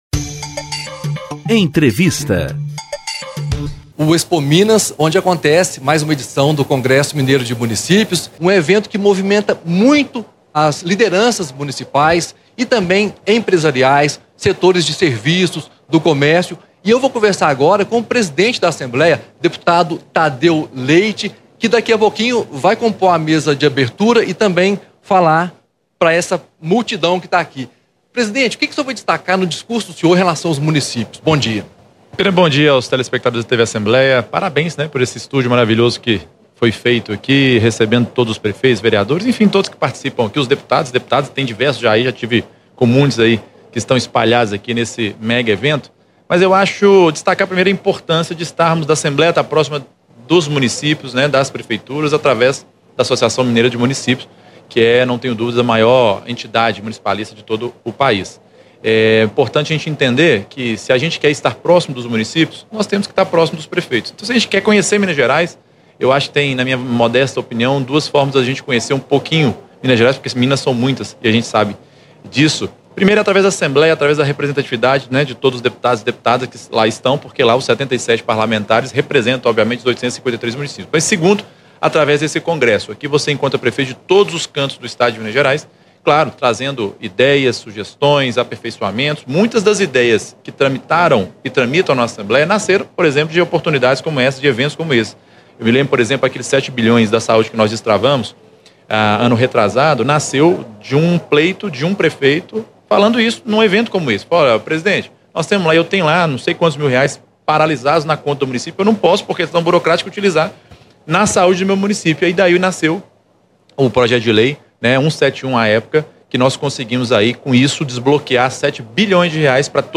Do estúdio da TV Assembleia no Expominas, em BH, o presidente do Legislativo concede entrevista antes de participar da abertura do 40º Congresso Mineiro de Municípios.